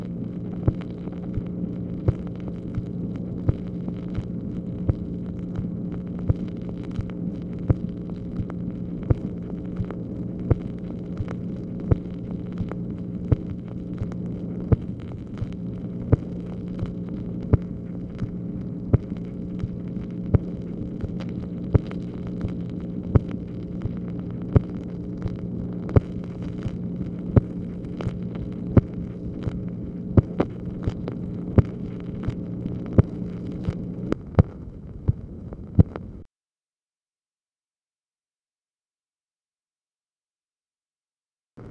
MACHINE NOISE, March 23, 1965
Secret White House Tapes | Lyndon B. Johnson Presidency